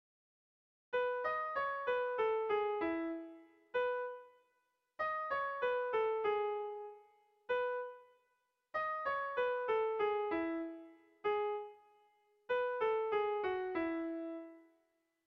Dantzakoa
A1A2